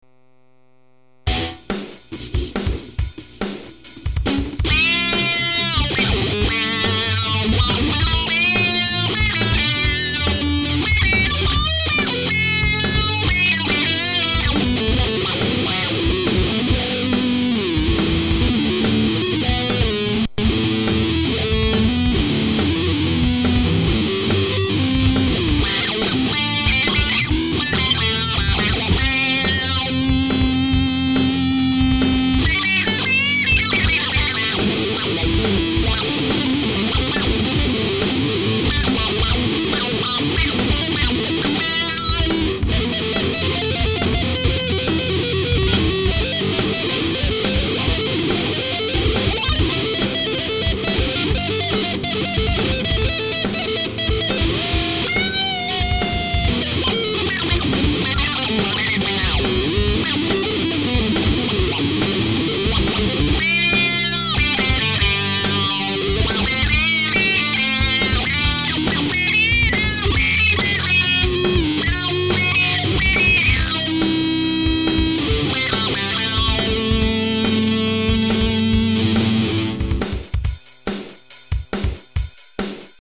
autowah1.mp3